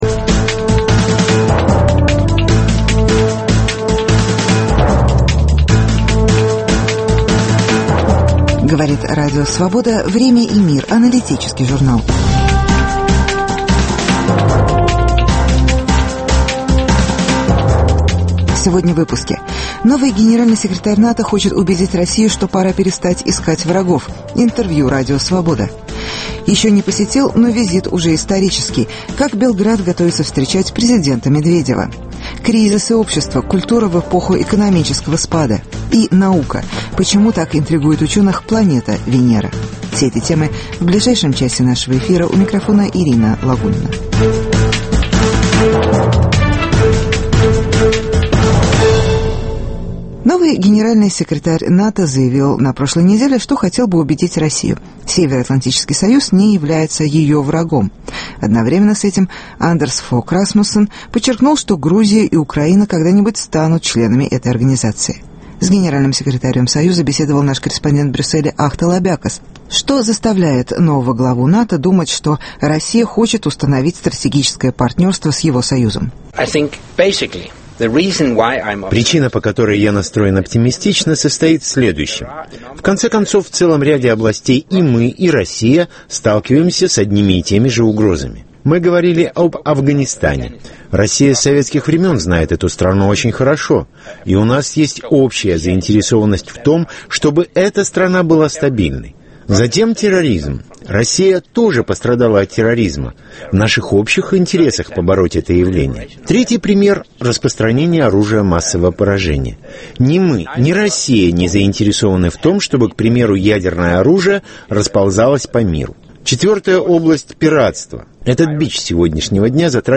Интервью с Генеральным секретарем НАТО. Как в Белграде готовятся к визиту президента России Д.Медведева. Кризис и общество: экономический спад и культура.